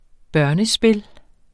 Udtale [ ˈbɔːl- ]